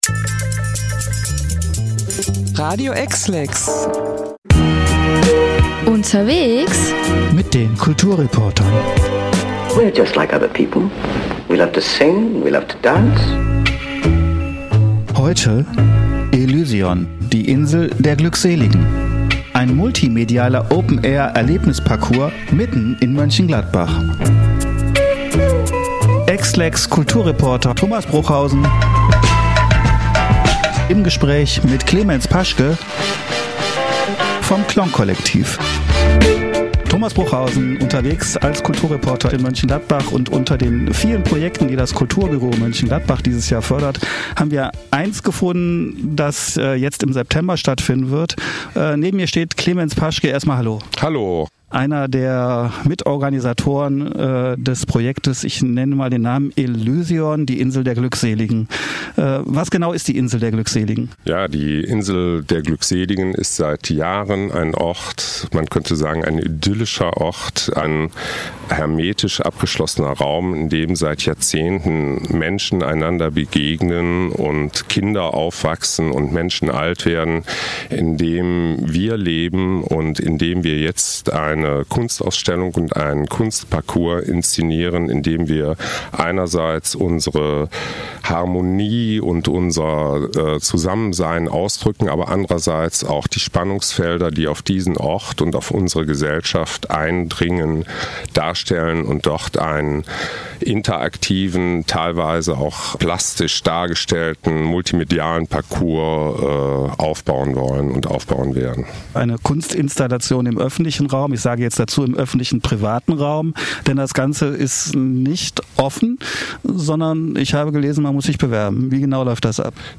Oder auch ohne Musik: